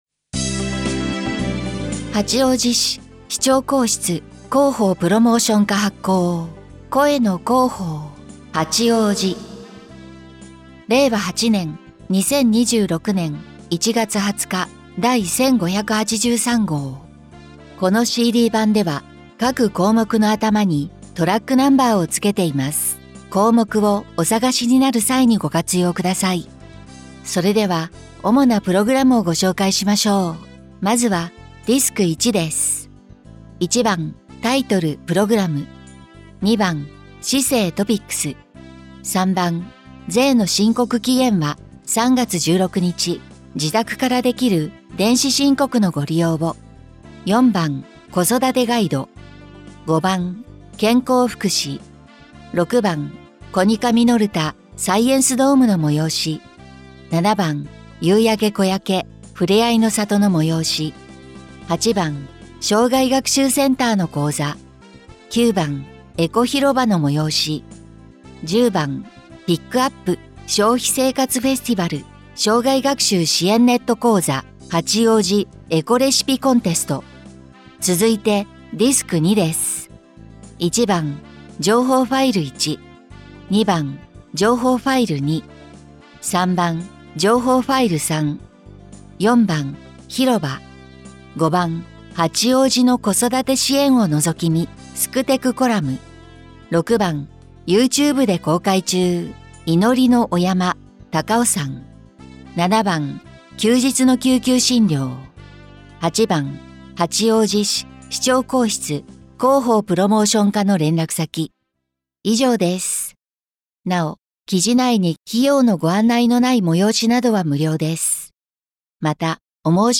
「声の広報」は、視覚障害がある方を対象に「広報はちおうじ」の記事を再編集し、音声にしたものです。